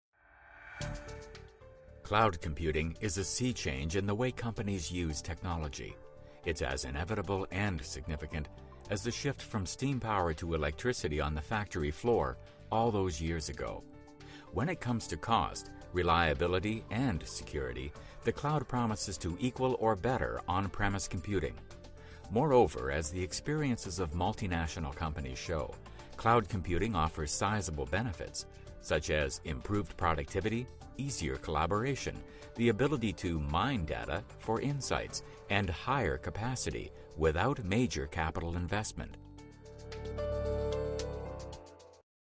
Voice Over; VO; Narrations;
mid-atlantic
Sprechprobe: Industrie (Muttersprache):
Warm, Friendly, Authoritative.